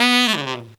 Index of /90_sSampleCDs/Zero-G - Phantom Horns/TENOR FX 2